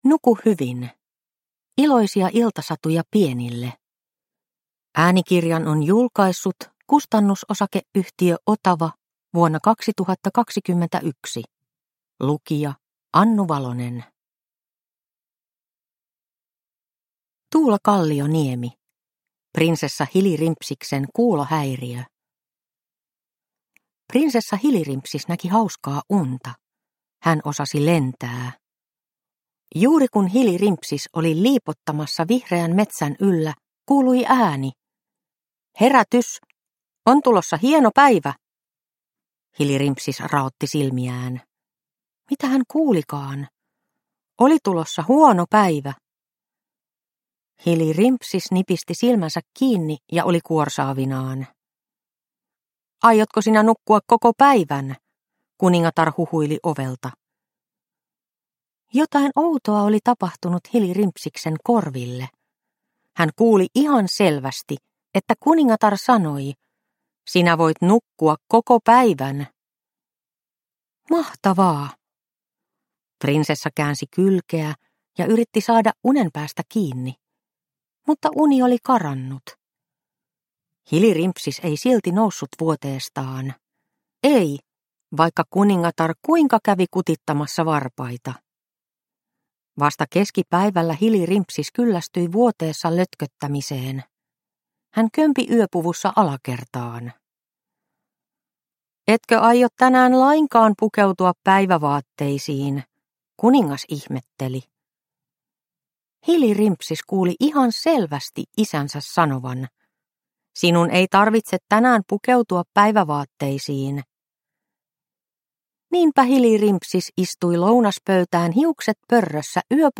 Nuku hyvin – Ljudbok – Laddas ner